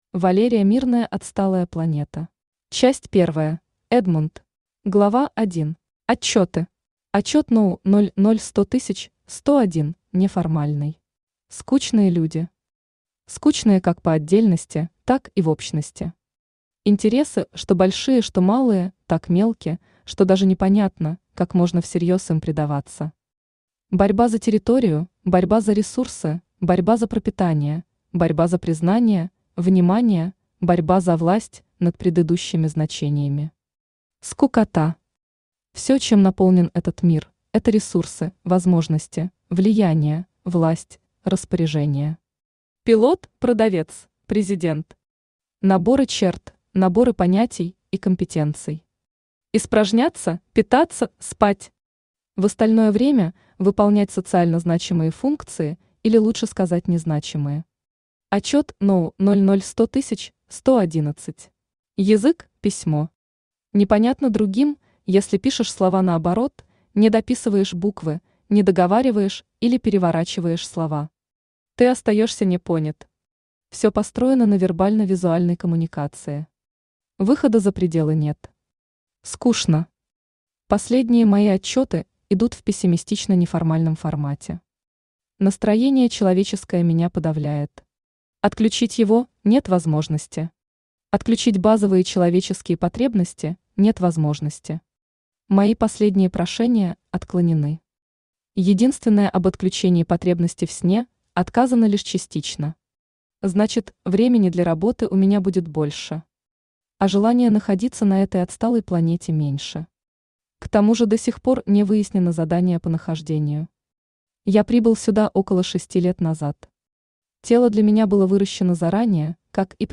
Аудиокнига Отсталая Планета | Библиотека аудиокниг
Aудиокнига Отсталая Планета Автор Валерия Мирная Читает аудиокнигу Авточтец ЛитРес.